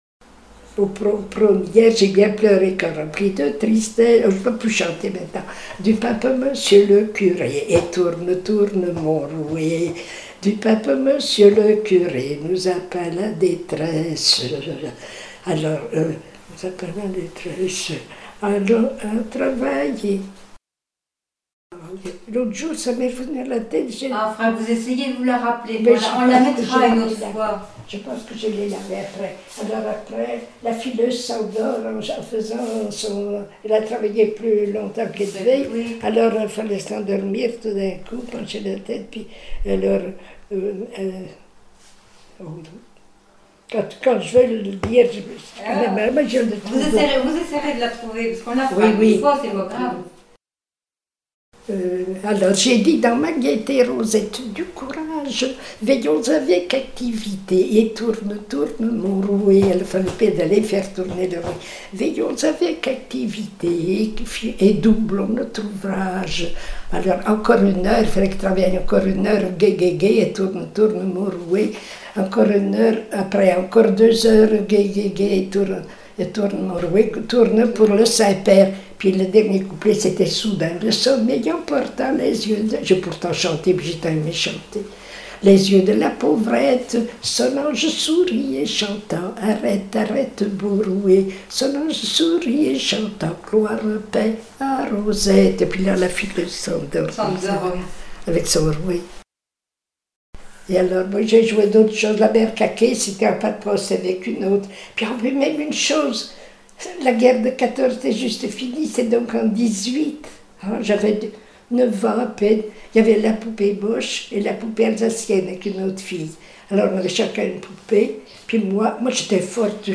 Mémoires et Patrimoines vivants - RaddO est une base de données d'archives iconographiques et sonores.
Comptines enfantines apprises à l'école